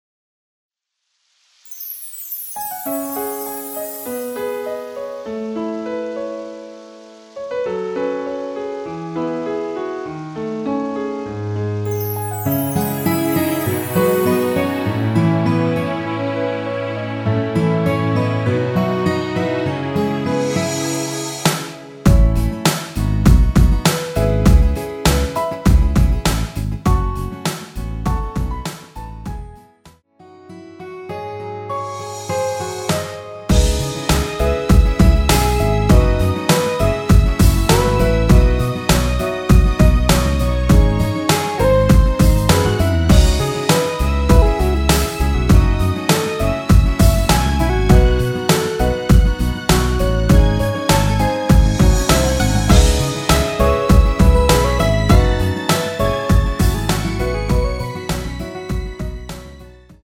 C#m
앞부분30초, 뒷부분30초씩 편집해서 올려 드리고 있습니다.
중간에 음이 끈어지고 다시 나오는 이유는